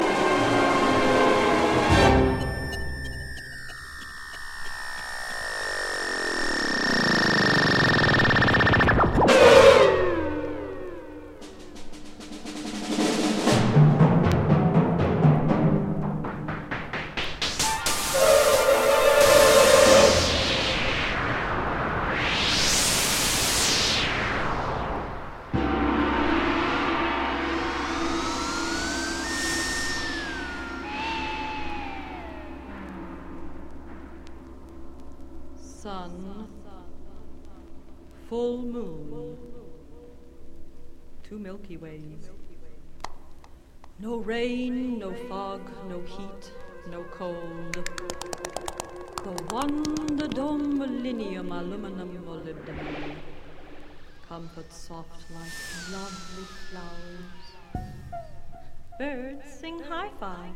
FOR ORCHESTRA, SYNTHESIZER, AND SOPRANO